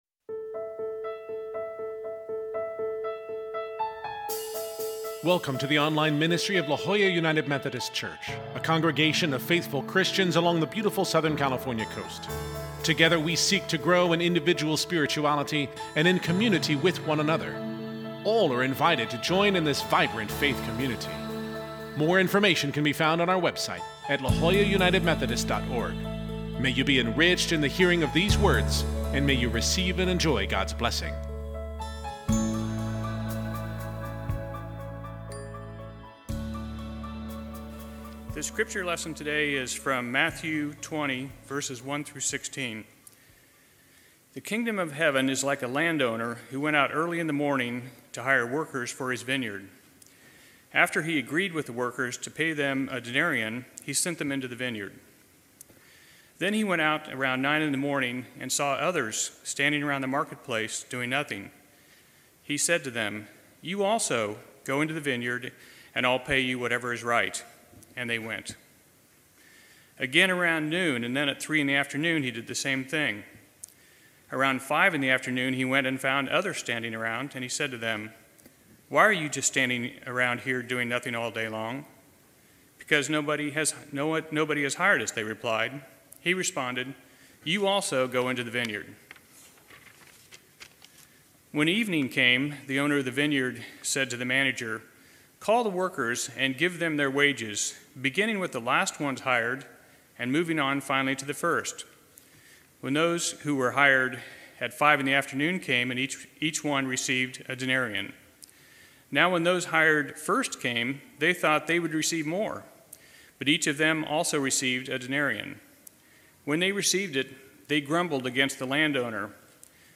This Sunday we continue our sermon series on the parables of Jesus, guided by the book Short Stories by Jesus: The Enigmatic Parables of a Controversial Rabbi by Amy-Jill Levine.